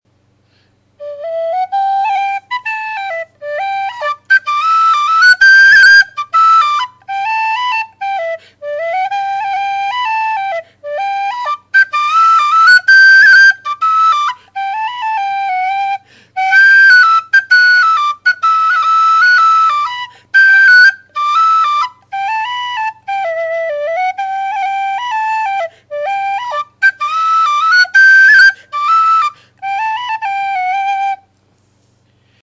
Alba Soprano D
Strong tone, with a loud and brash second octave. Has an underlying hiss that increases as you go up the range.
Sound clips of the whistle:
In my Off to California clip, the need to take a breath caught me by surprise near the end of the tune